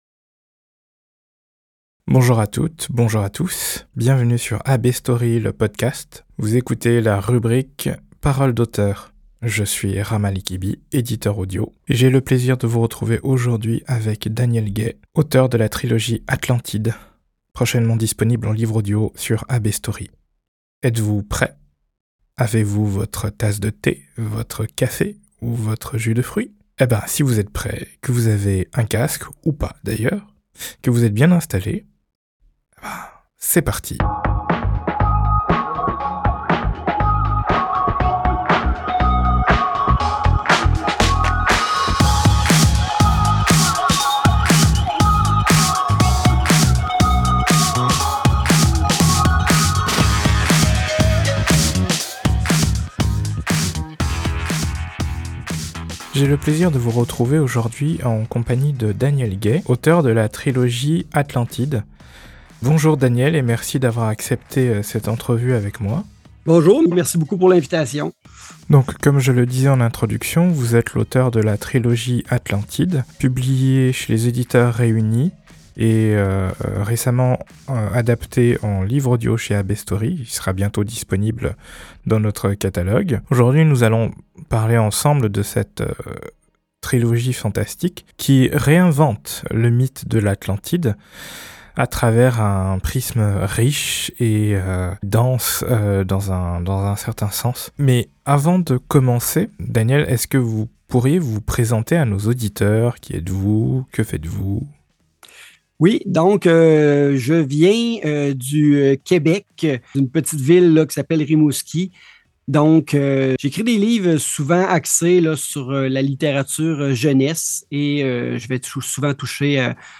entrevue-atlantide.mp3